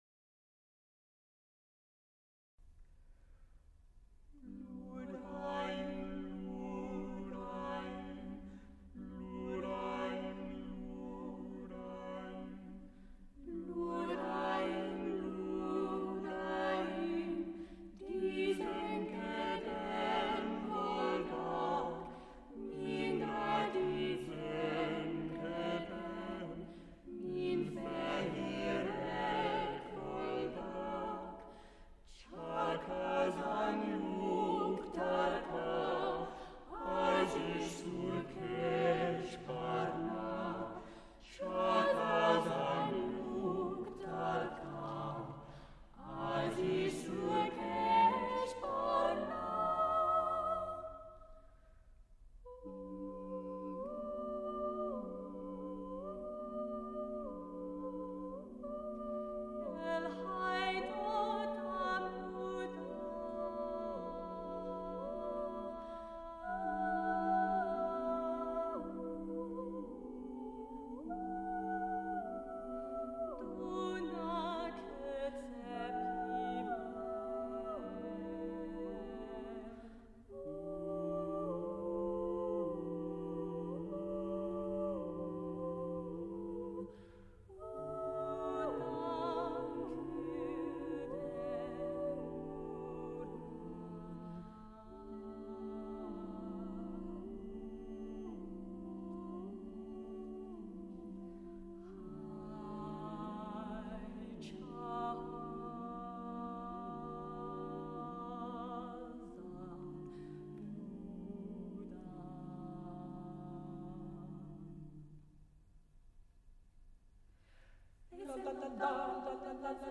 (SSATB)